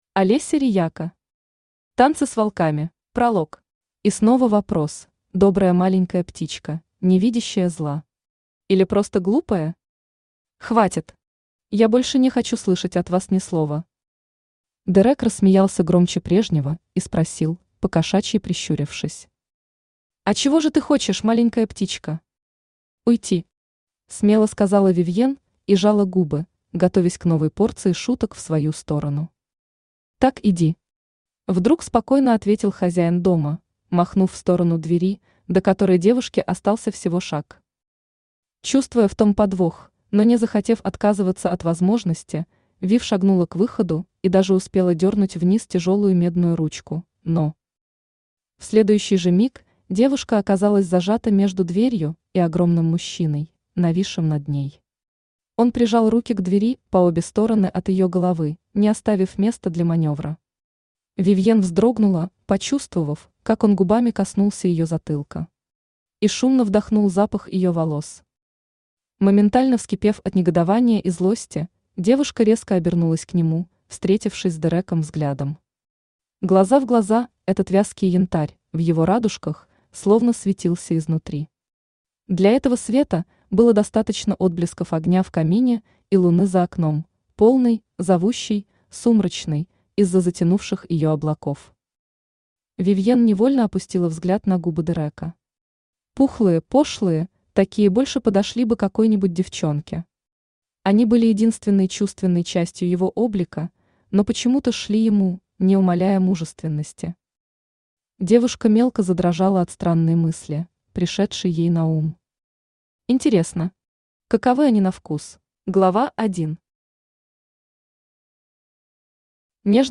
Аудиокнига Танцы с волками | Библиотека аудиокниг
Aудиокнига Танцы с волками Автор Олеся Рияко Читает аудиокнигу Авточтец ЛитРес.